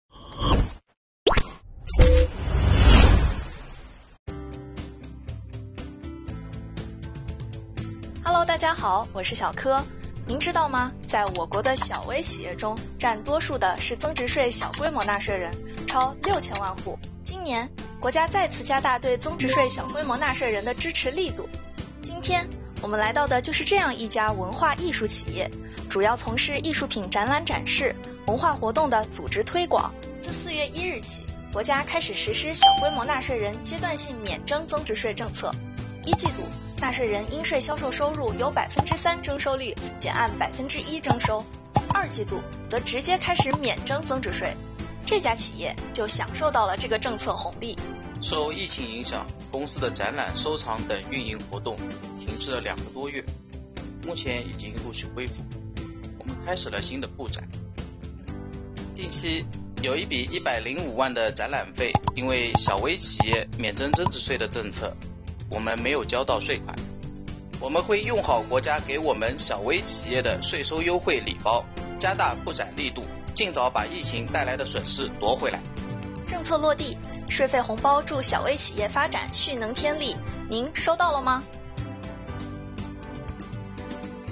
随着增值税小规模纳税人免征增值税等优惠政策的落地生效，许多小微企业渡过疫情难关，重拾发展信心，跑出发展“加速度”。这家文化艺术企业，便是政策优惠受益者之一，让我们跟着税务主播一起走近看看吧~